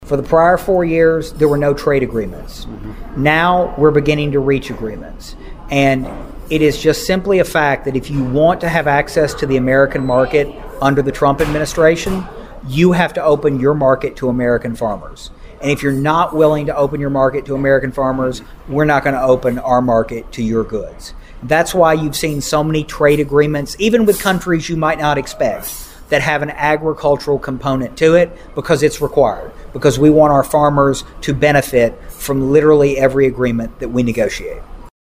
Vaden told Your Ag Edge that the U.S. agriculture is back in the game with trade.